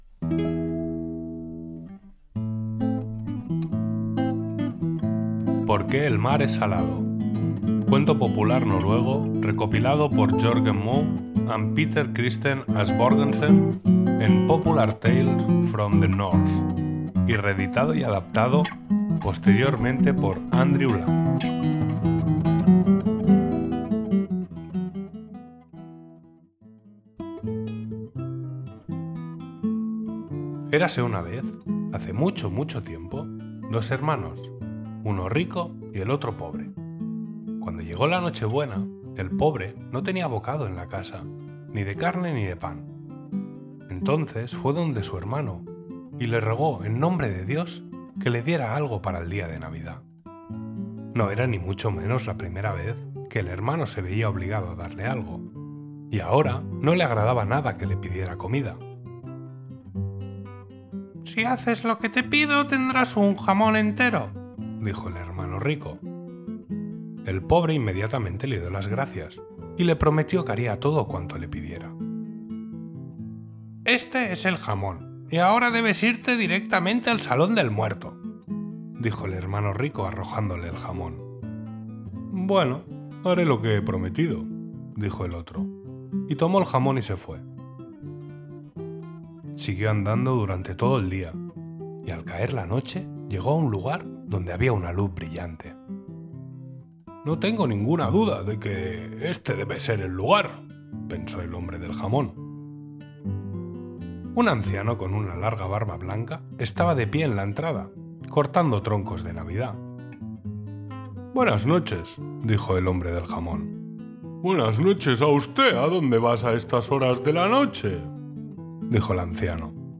Cuento narrado: Por qué el mar es salado
por-que-el-mar-es-salado-cuento-noruego.mp3